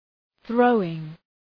Προφορά
{‘ɵrəʋıŋ}